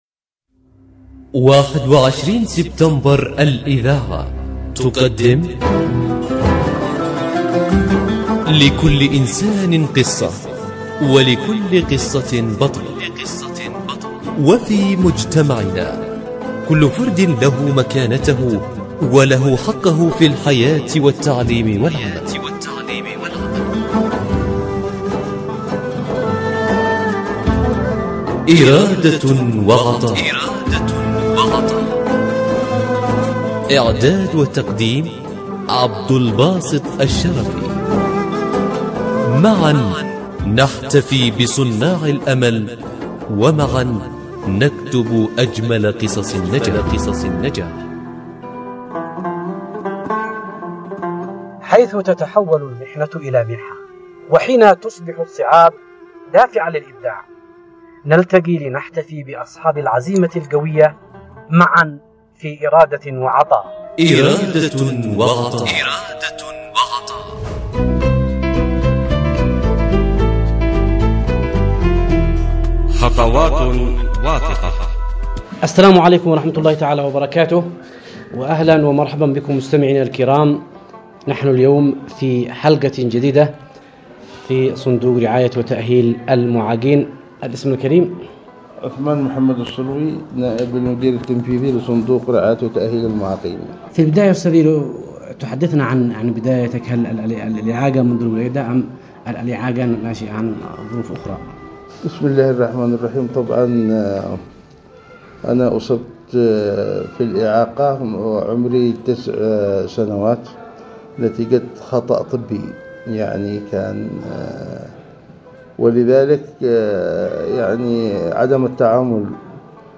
برنامج إرادة وعطاء، يأخذكم في رحلة إذاعية قصيرة ، نستكشف خلالها عالماً مليئاً بالتحدي والإصرار. نسلط الضوء على قصص ملهمة لأشخاص من ذوي الهمم، أثبتوا أن الإعاقة لا تحد من العطاء بل تزيده قوة وإبداعاً.